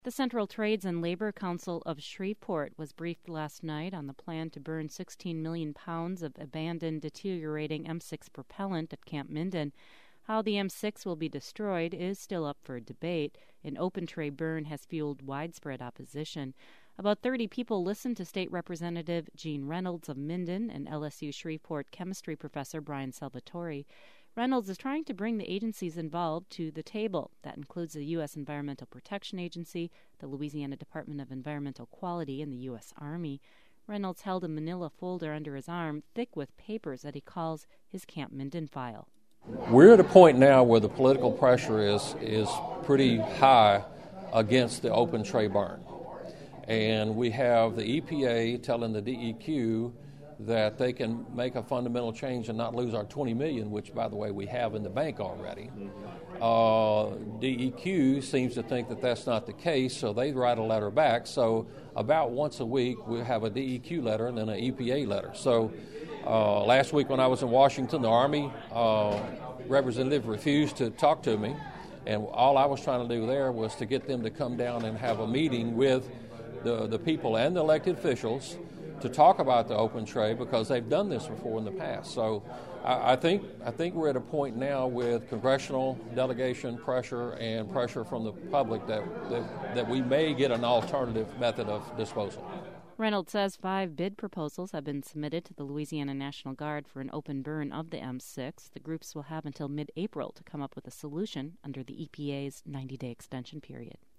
State Rep. Gene Reynolds spoke Tuesday to union members in Shreveport about the open burn proposal for Camp Minden.